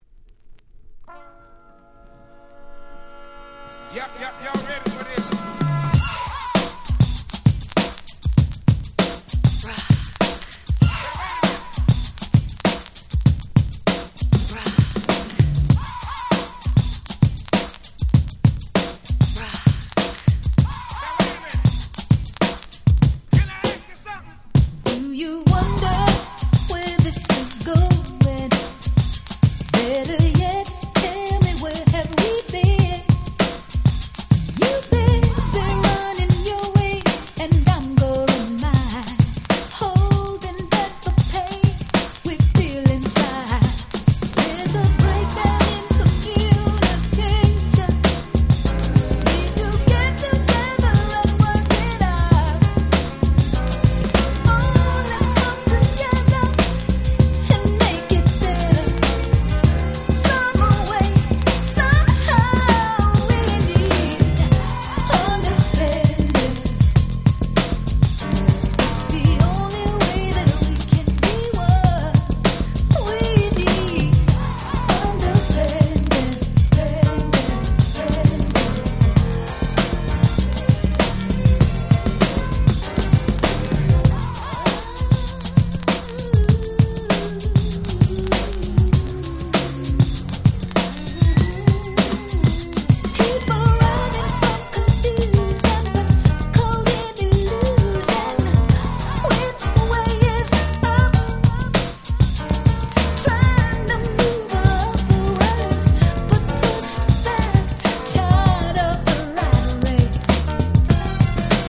91' Nice R&B !!
軽快なHip Hop Beatに女性Vocalが際立つ良曲！